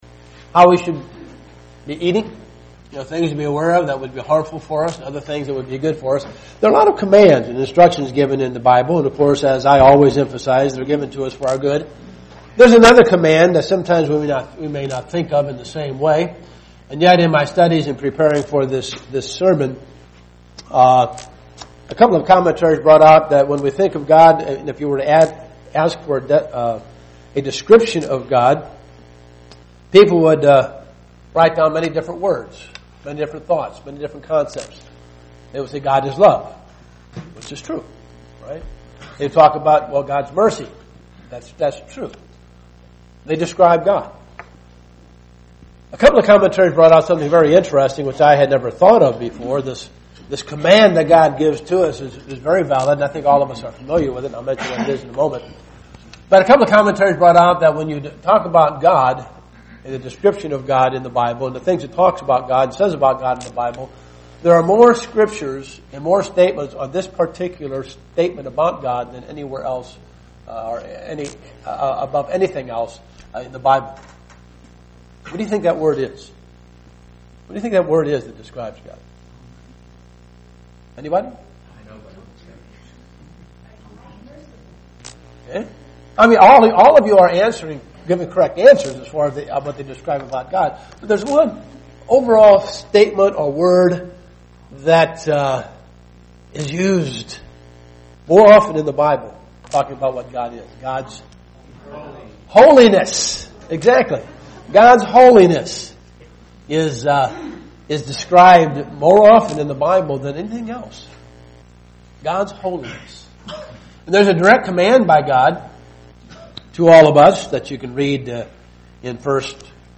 Given in Dayton, OH
UCG Sermon Studying the bible?